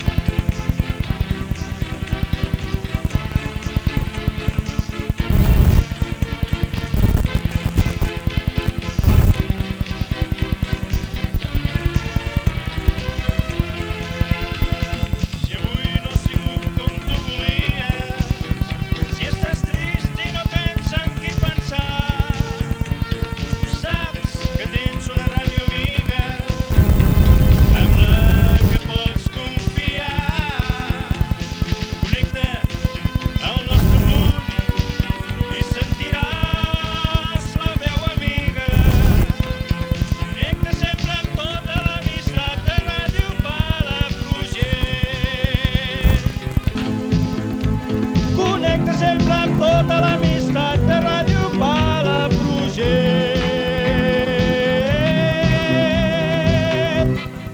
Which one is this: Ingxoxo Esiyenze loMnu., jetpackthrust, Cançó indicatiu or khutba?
Cançó indicatiu